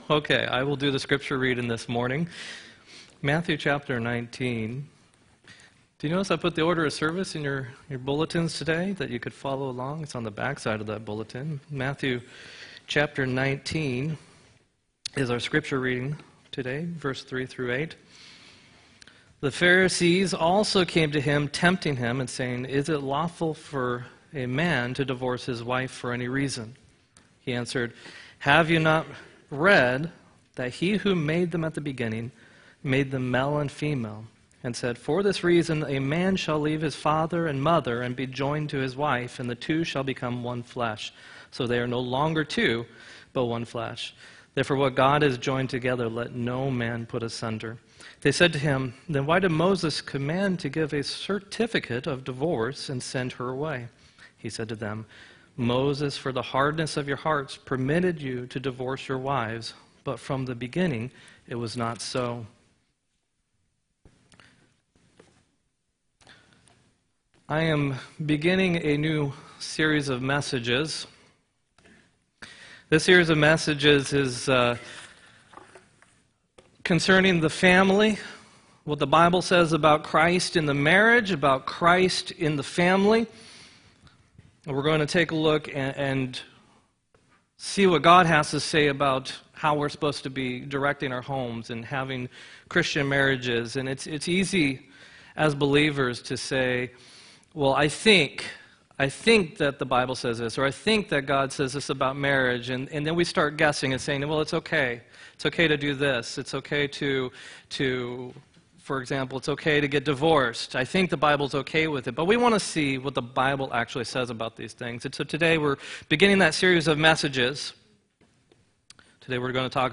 5-19-18 sermon